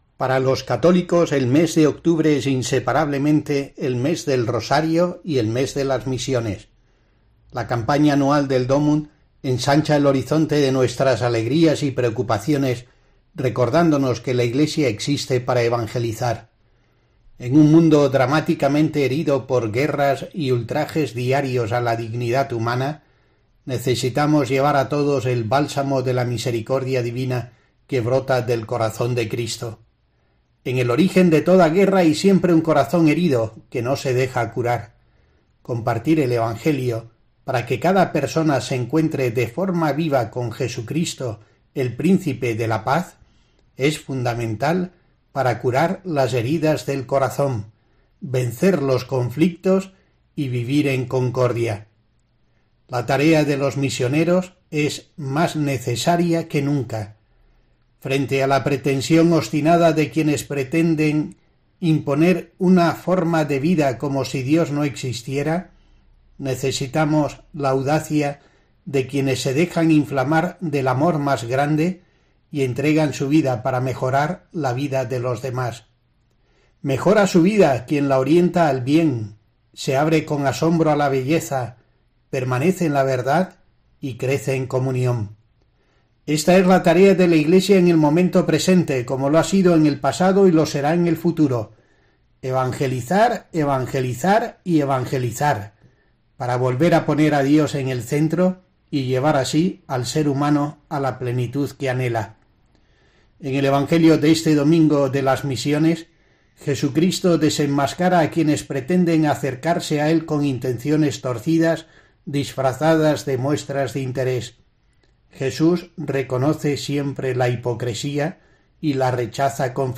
Monseñor Rico Pavés recuerda, en su reflexión semanal para 'El Espejo de Asidonia-Jerez', la importancia de esta jornada anual que celebvraremos el domingo 22 de octubre